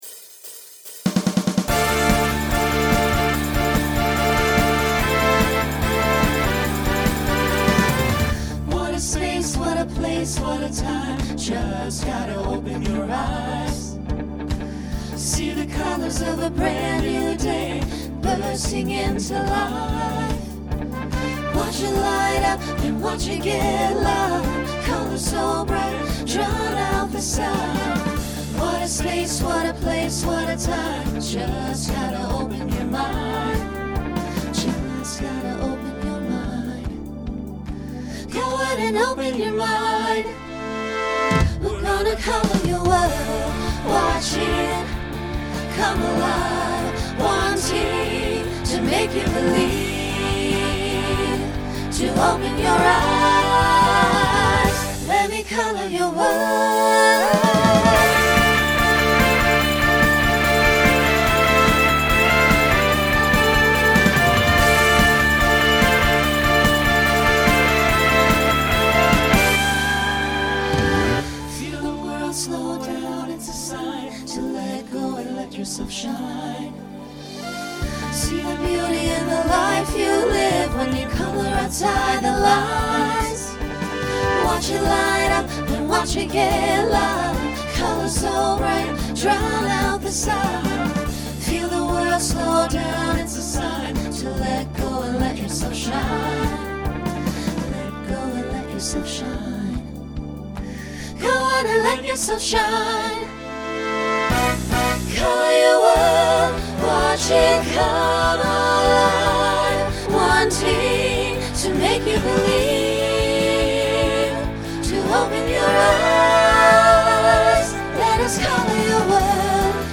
Pop/Dance
Voicing SAB